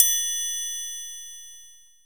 OPEN TRI 1.wav